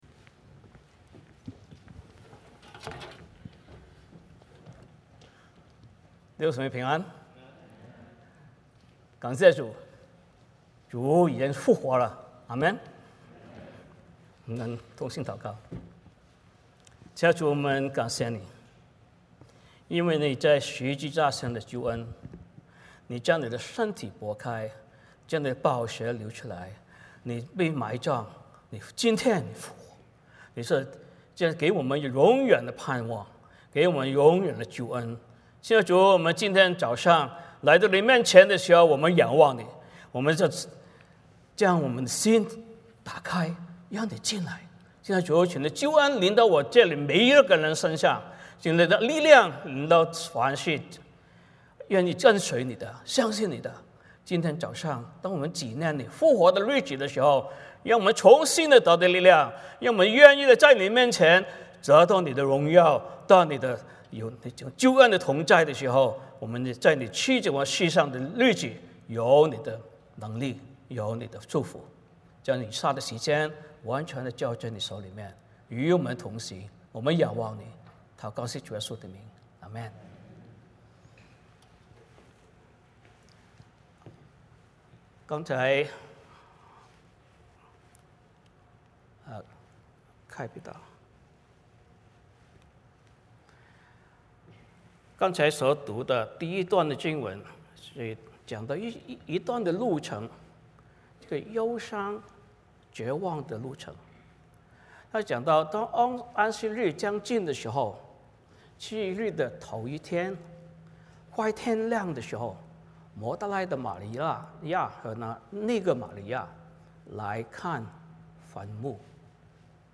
马太福音 28:1-20 Service Type: 主日崇拜 欢迎大家加入我们的敬拜。